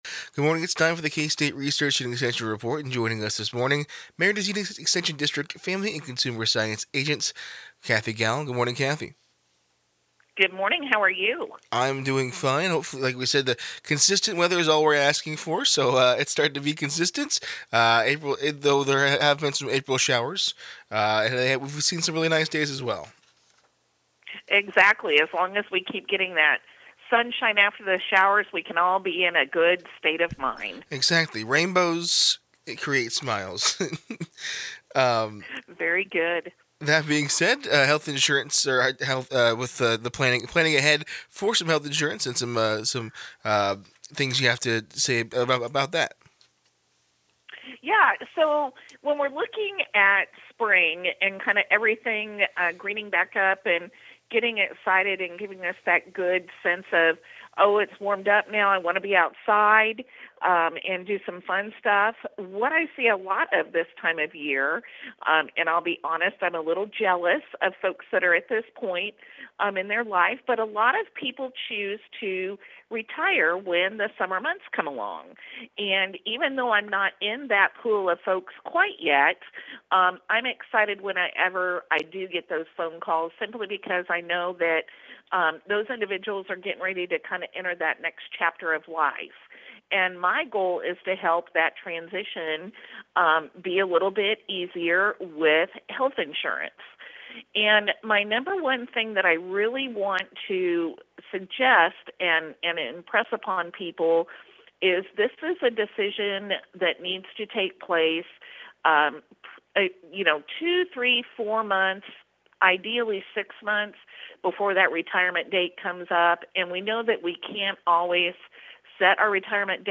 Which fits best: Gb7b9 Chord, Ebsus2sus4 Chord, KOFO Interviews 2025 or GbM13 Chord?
KOFO Interviews 2025